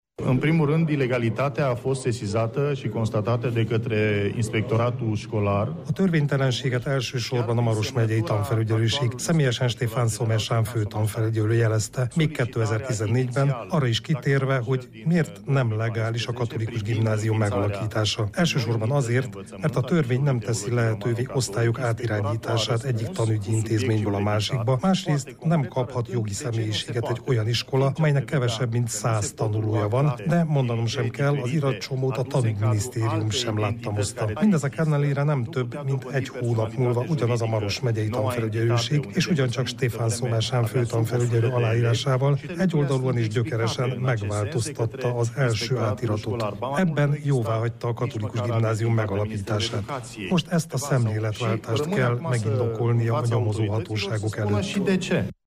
Továbbra is törvényellenesnek tartja a marosvásárhelyi Római Katolikus Teológiai líceum létrehozását – jelentette ki mai sajtótájékoztatóján Marius Pascan.
Marius Pascan szenátor arról, hol történt a törvénysértés: